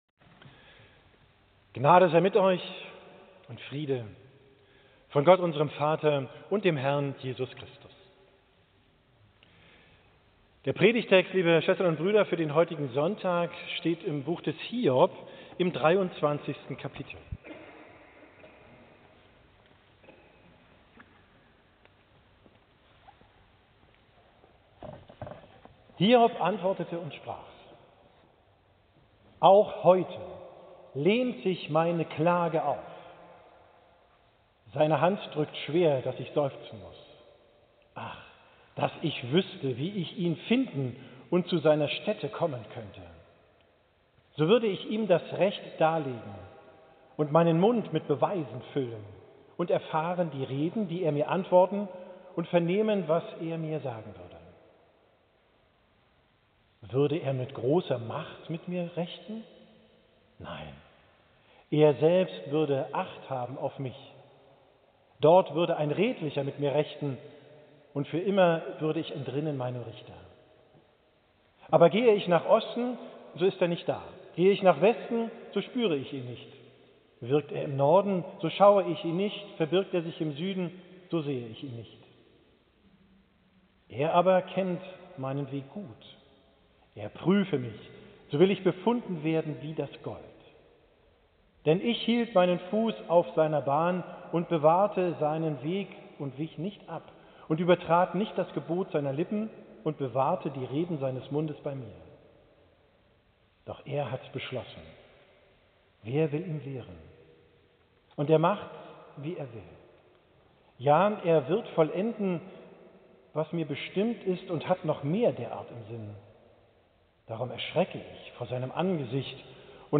Predigt vom 12.